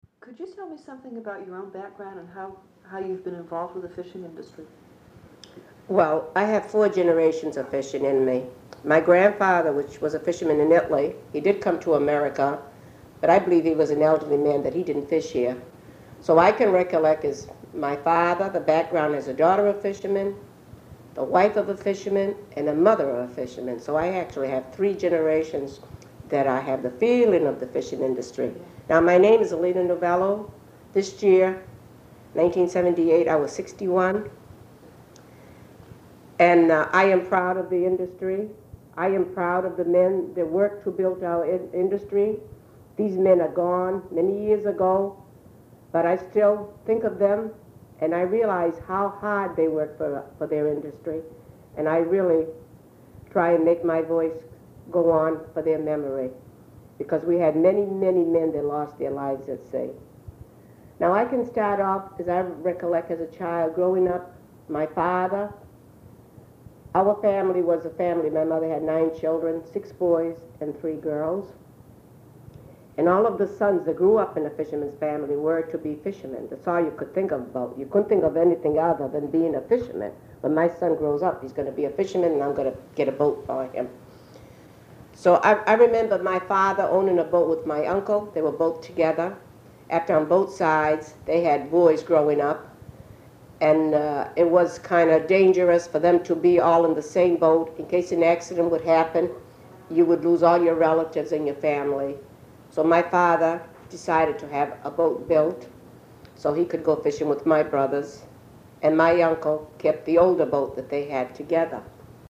Oral History Overview
In 2003, most of the original sound cassettes were converted to compact discs by the Sawyer Free Library.